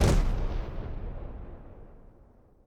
impact